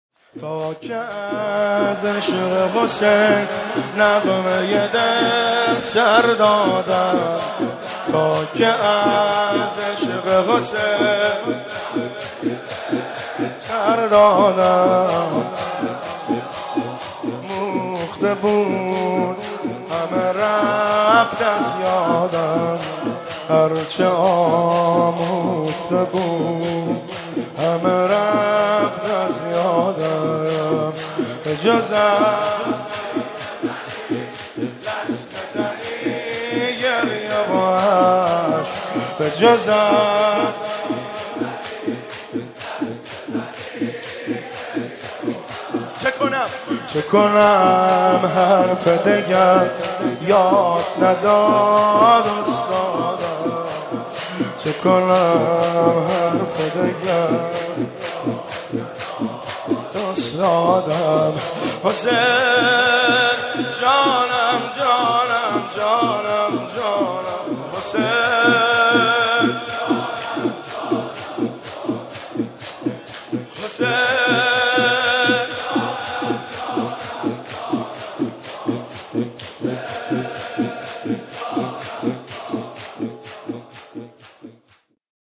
مناسبت : وفات حضرت زینب سلام‌الله‌علیها
قالب : شور